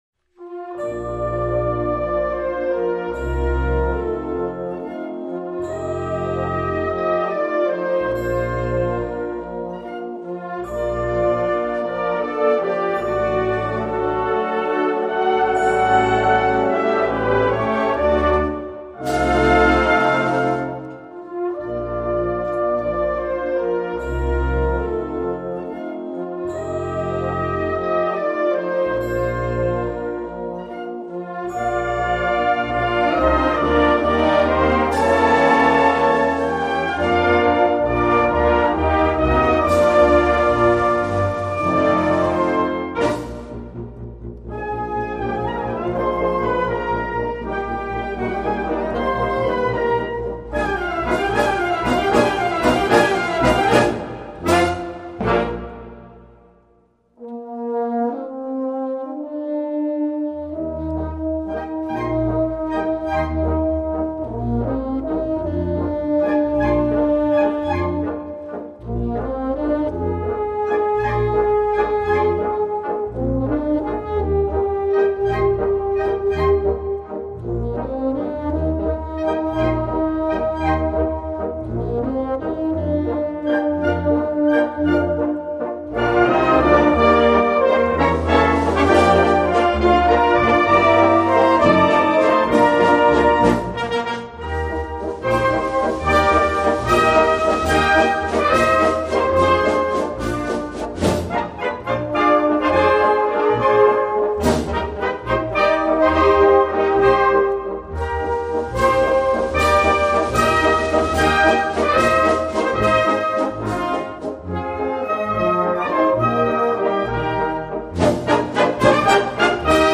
Gattung: Walzer für Blasorchester
Besetzung: Blasorchester